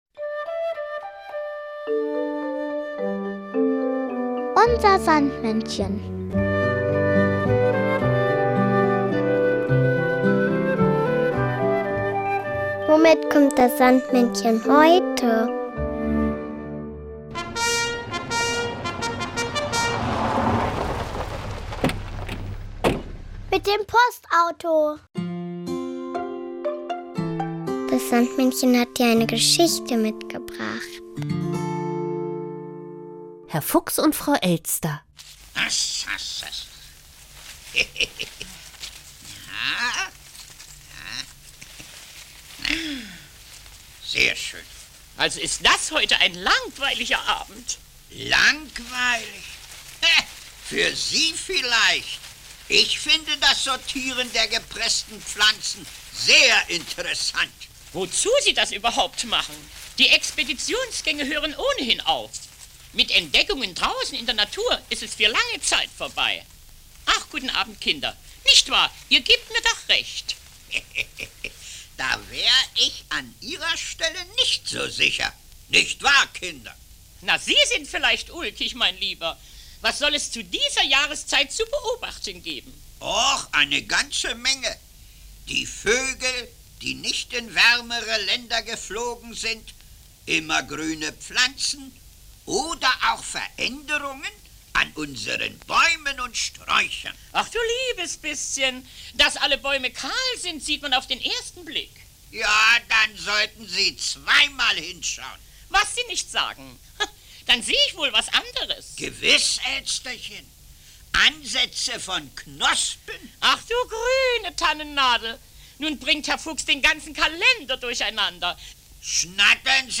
mitgebracht, sondern auch das Kinderlied "Nebel, Nebel" von Detlef